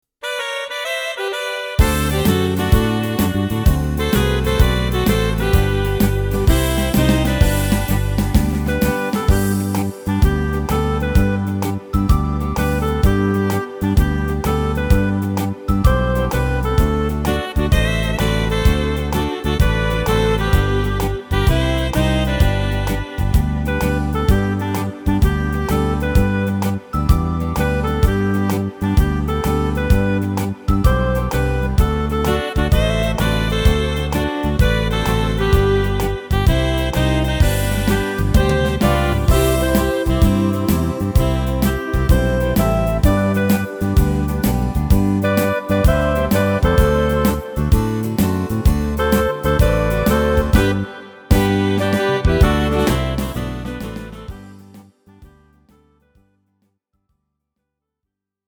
Tempo: 128 / Tonart:  G / G# – Dur